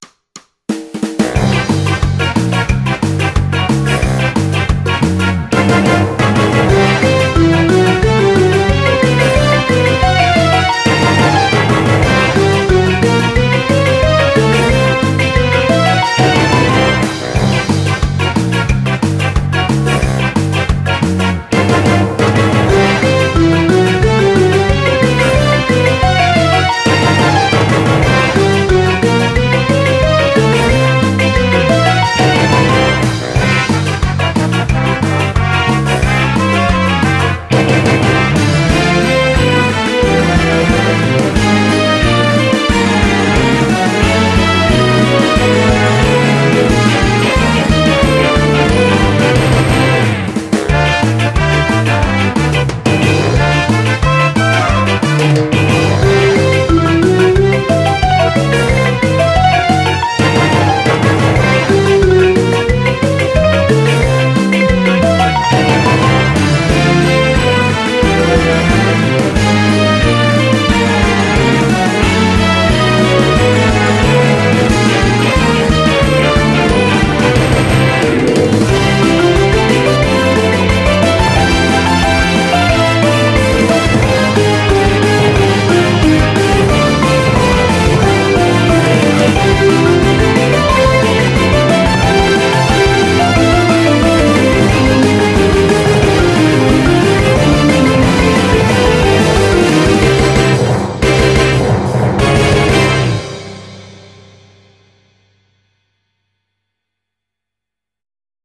BPM180
Audio QualityPerfect (Low Quality)